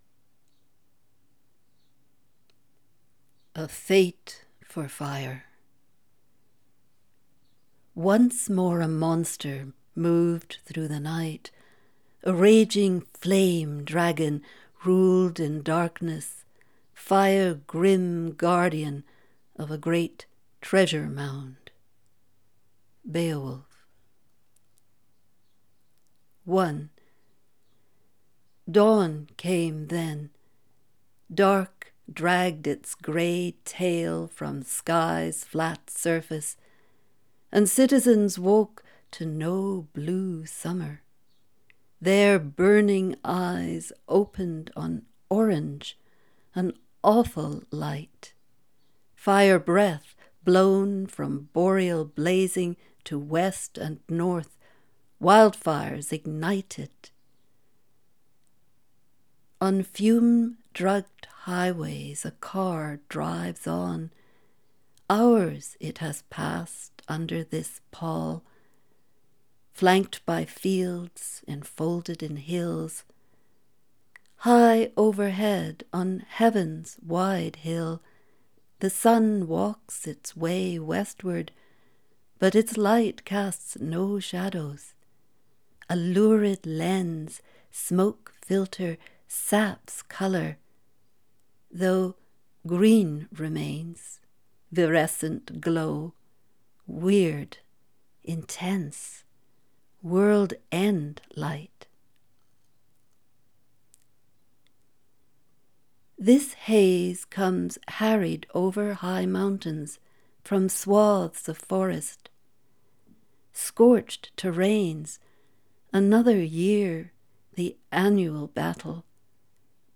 Recording: "A fate for fire" – an excerpt from part 1 of the poem